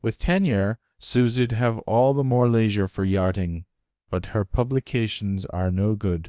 text-to-speech voice-cloning